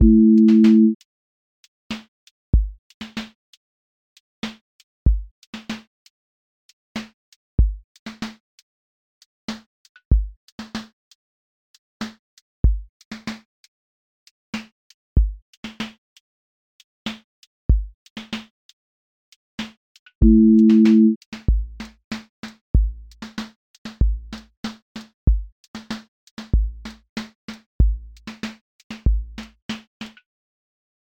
neo soul lilt
neo-soul live lilt pocket
• voice_kick_808
• voice_snare_boom_bap
• voice_hat_rimshot
• voice_sub_pulse
• tone_warm_body
• motion_drift_slow